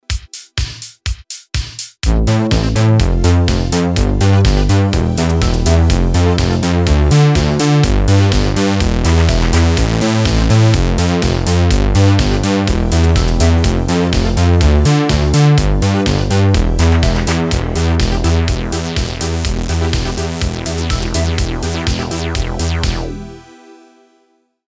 Прикололся...Тайросом2 наиграл басы Буга ,которые ,как бы в стиле самоиграйки )))))))) Громковато,однако...Но это просто прикол Вложения Moog feat Tyros2.mp3 Moog feat Tyros2.mp3 772,6 KB · Просмотры: 664